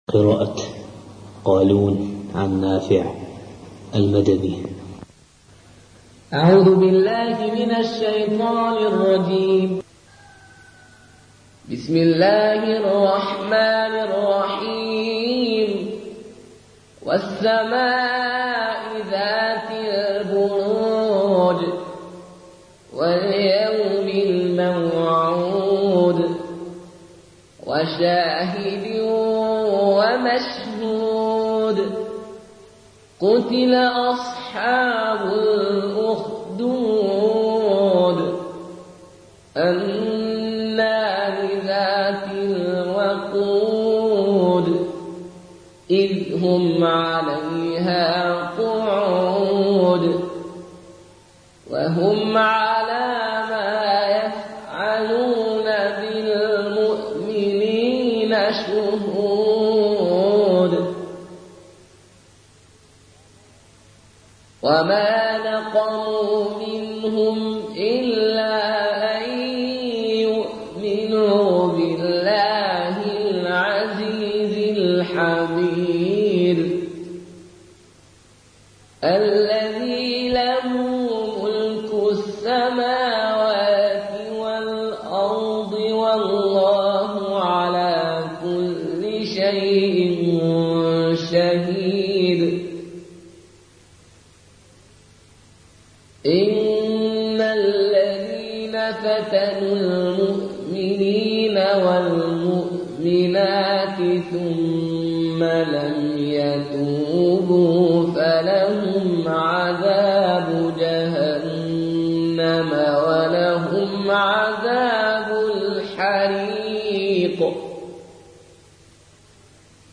(رواية قالون)